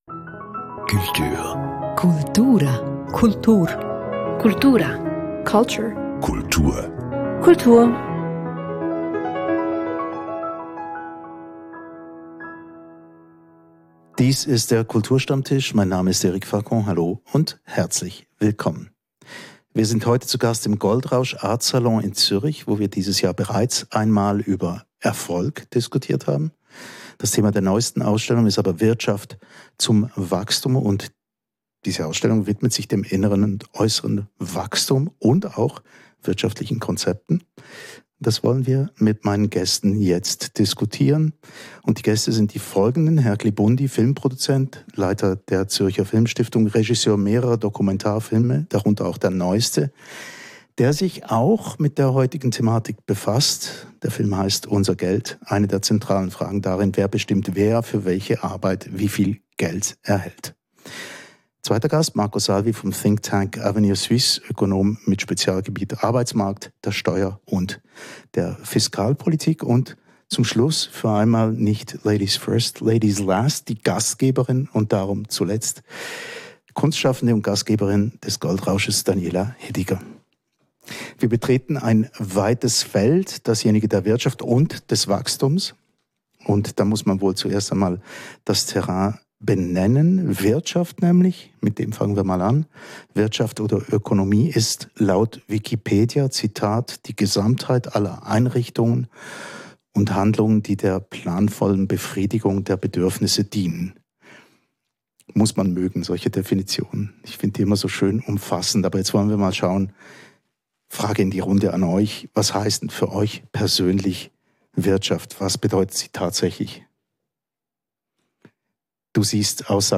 Wird dieses Wachstum immer nur wirtschaftlich definiert – oder gäbe es auch persönliche und gesellschaftliche Faktoren? Und wie könnte die Kultur involviert werden? Diesen Fragen geht eine Zürcher Kunst-Ausstellung nach, in deren Rahmen der Kulturstammtisch stattfand.